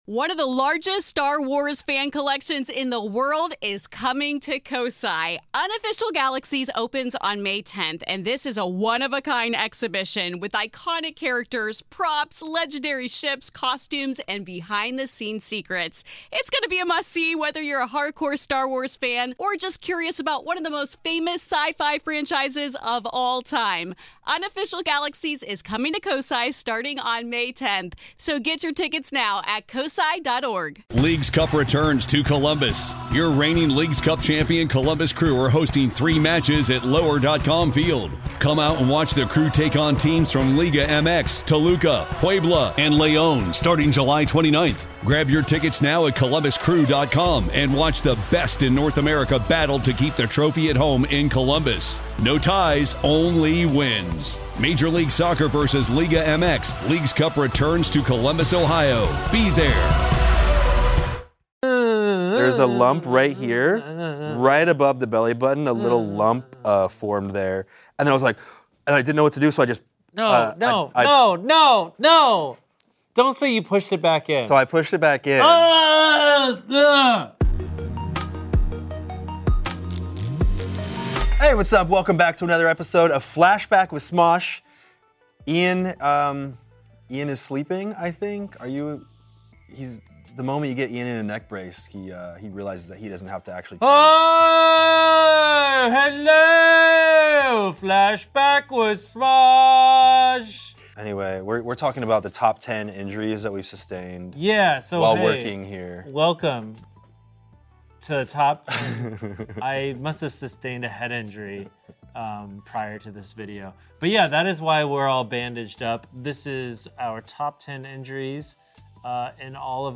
Ian and Anthony discuss and rank(?) injuries sustained from making Smosh videos.